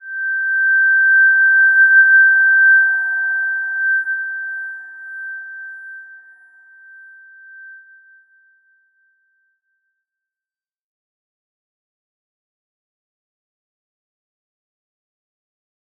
Slow-Distant-Chime-G6-mf.wav